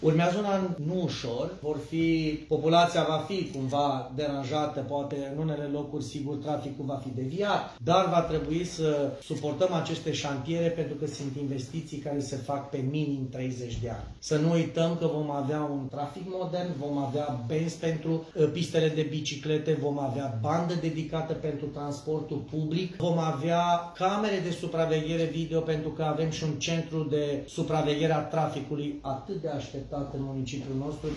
Primarul Gabriel Pleșa a vorbit despre viitoarele șantiere.